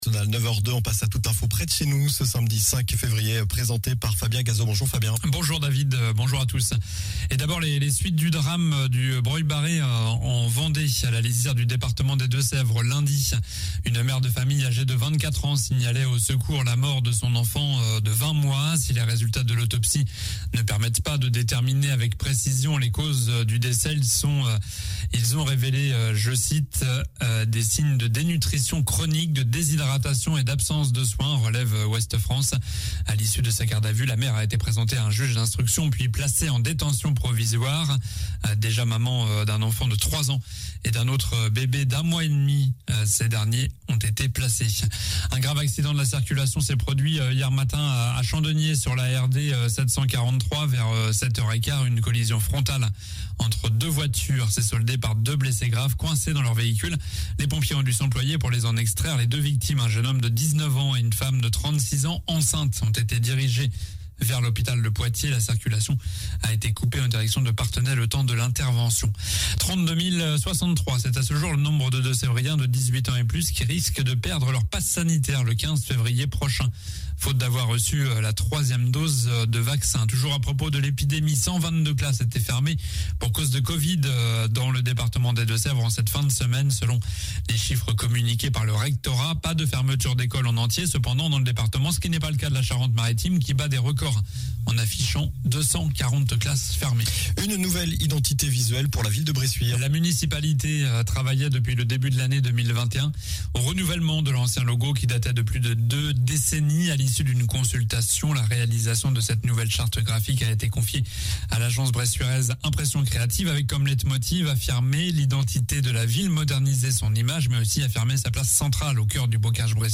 Journal du samedi 05 février